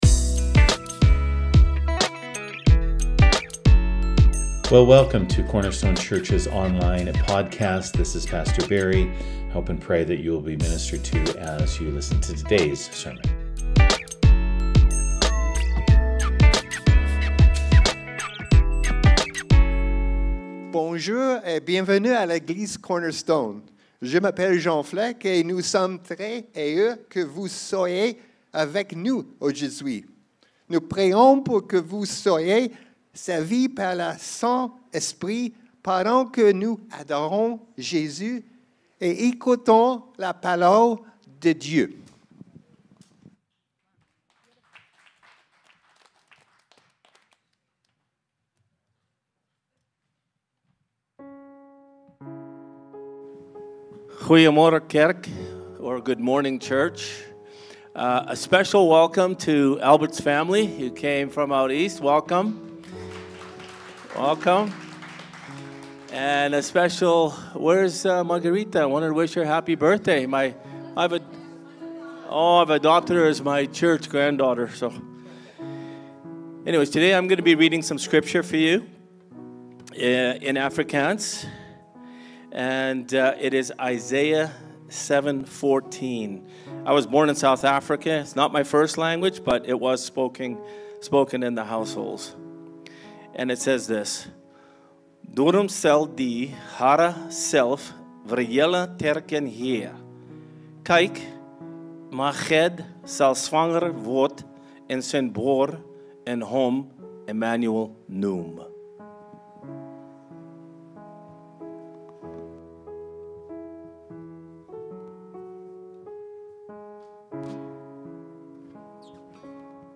In this special Christmas service, the Christmas scriptures are read in languages from all around the world.